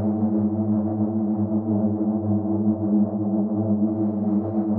SS_CreepVoxLoopA-03.wav